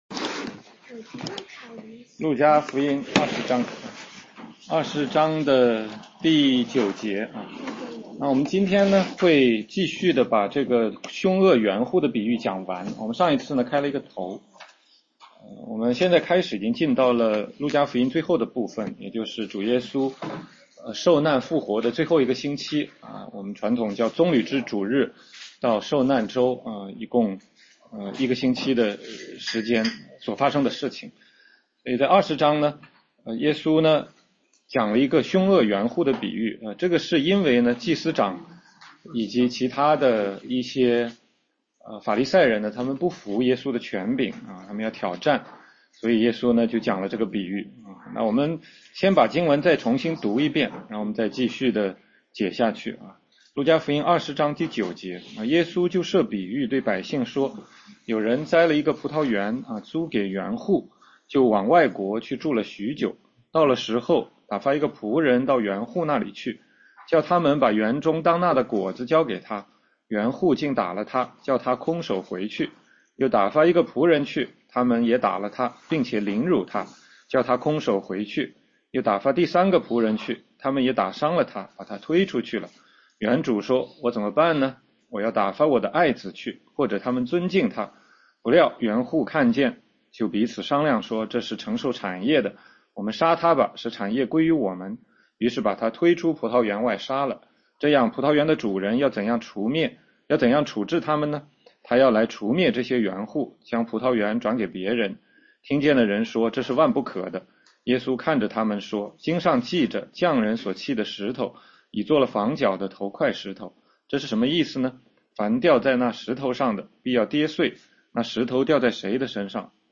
16街讲道录音 - 路加福音20章9-19节：凶恶园户的比喻（下）
全中文查经